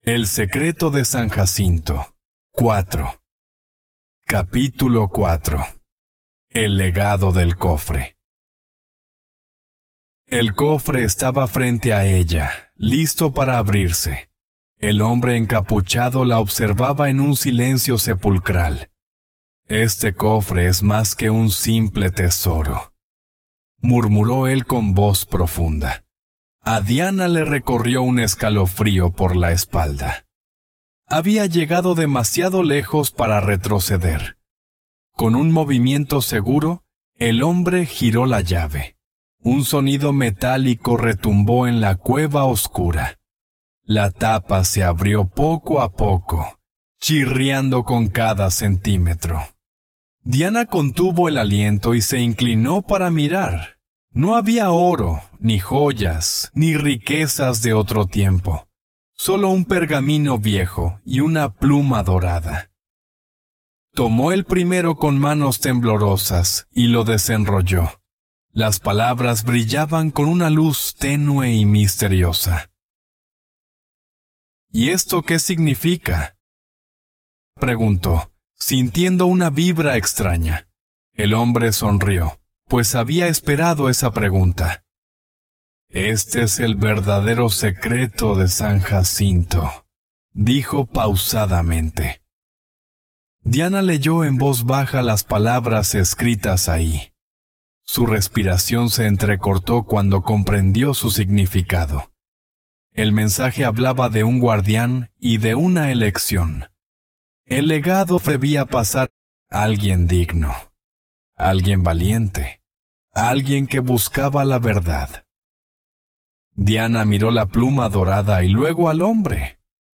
Spanish online reading and listening practice – level B1